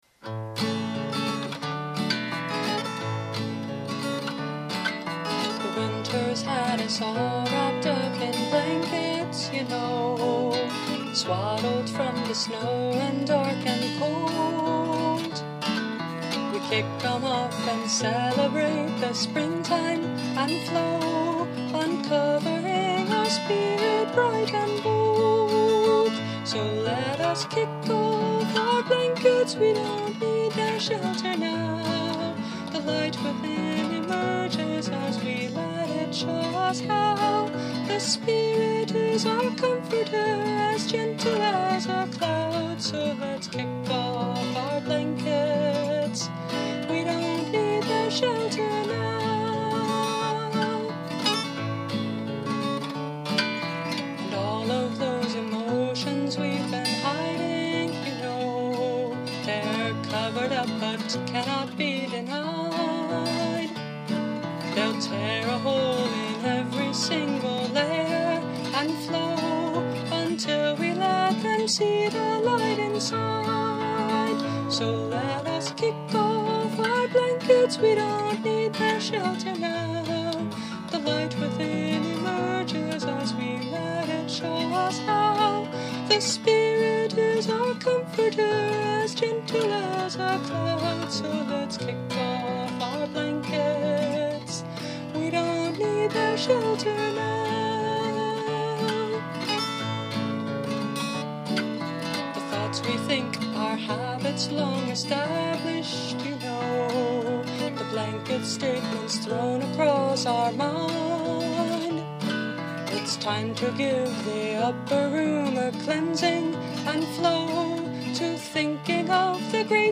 Instrument: Tempo – Seagull Excursion Folk Acoustic Guitar
(Capo 2)